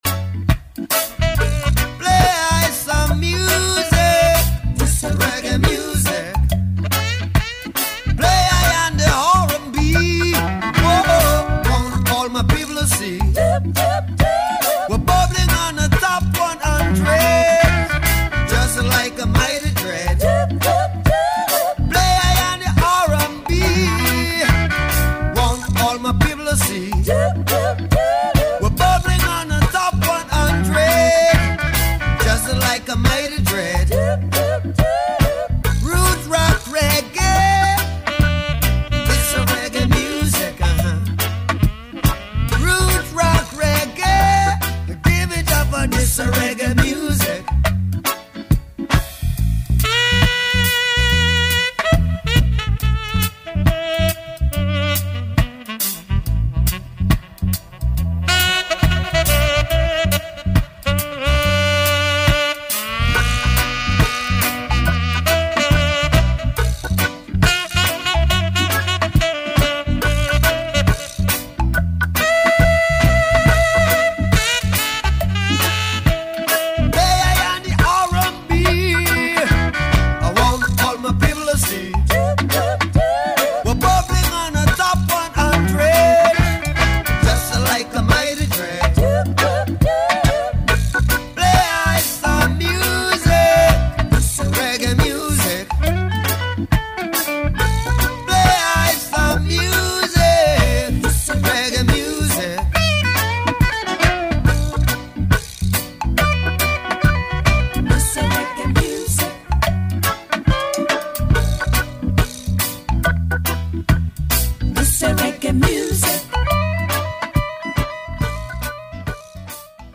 - DUBPLATES SELECTION -